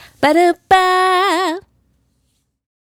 Riff 085-F#.wav